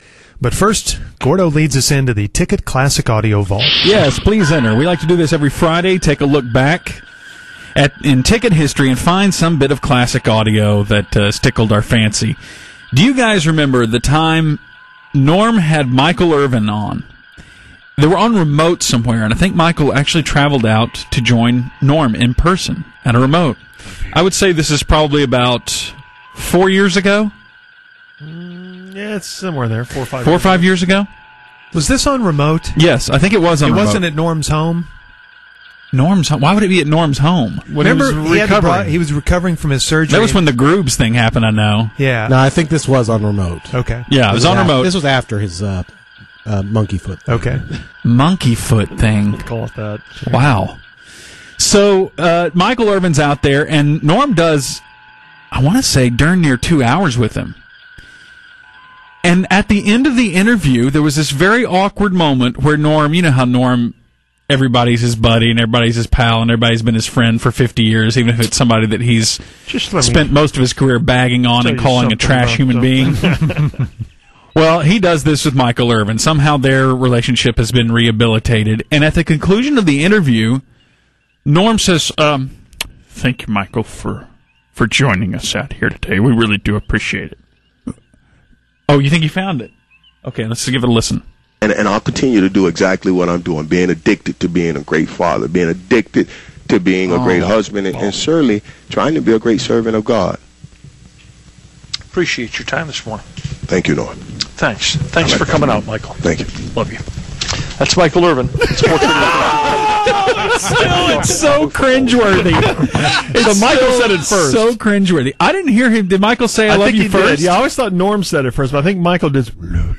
This is audio of their off-air conversation pulled by the Ticket.